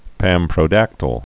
(pămprō-dăktəl) or pam·pro·dac·ty·lous (pămprō-dăktə-ləs)